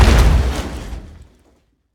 car-stone-impact.ogg